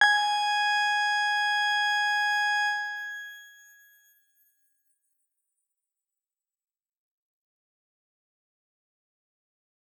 X_Grain-G#5-pp.wav